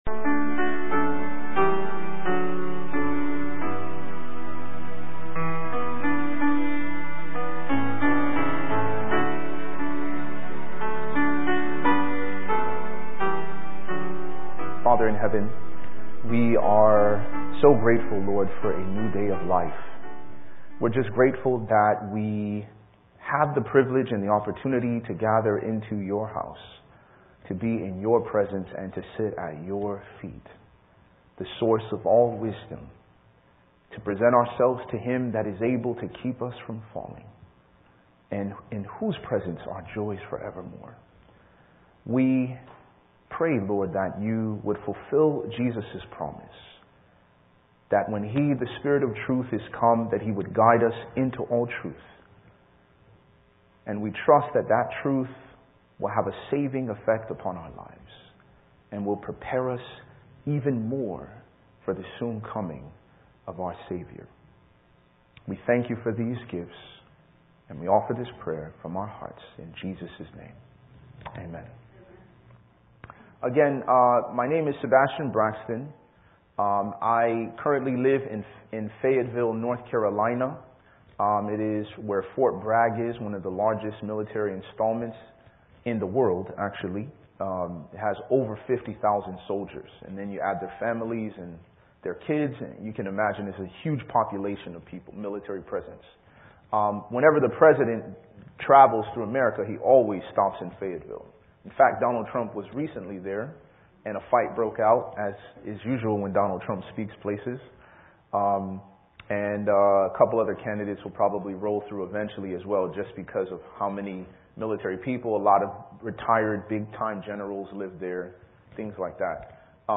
Note: This series is a teaching seminar especially intended for the Bible student who wishes to be earnestly challenged by a mind-taxing study plan.